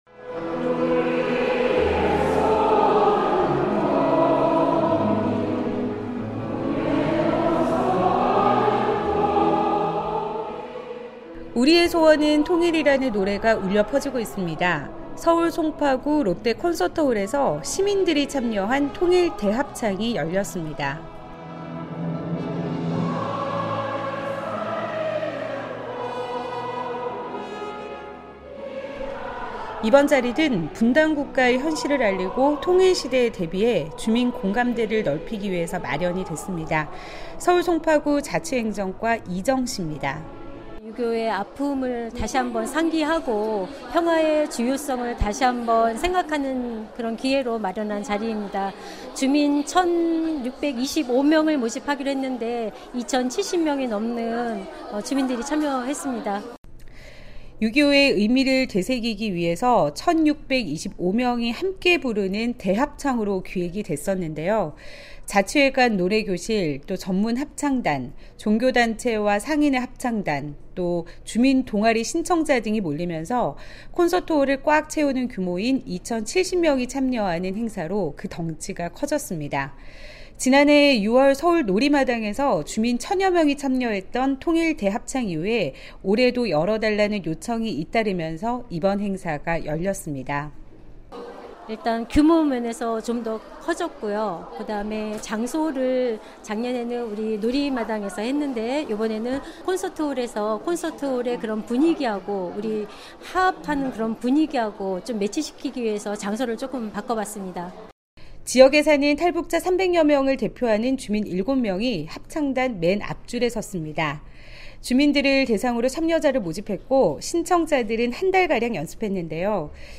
2천명이 함께 부른 통일대합창